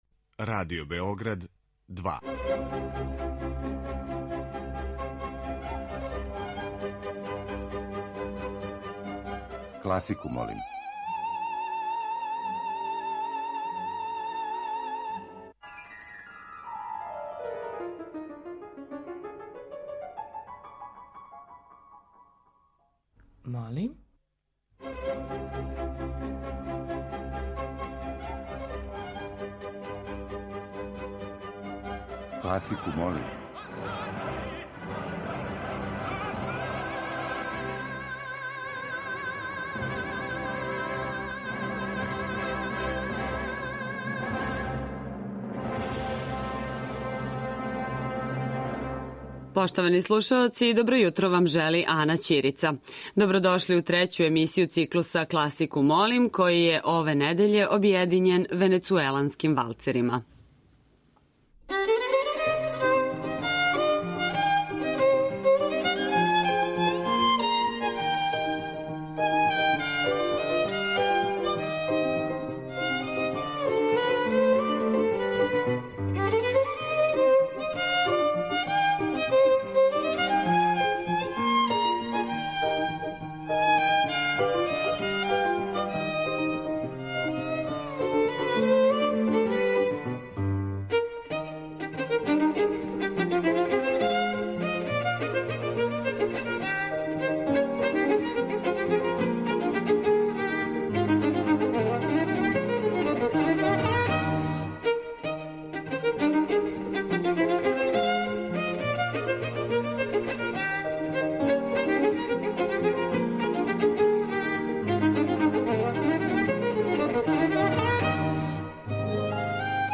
Избор за недељни хит класичне музике РБ2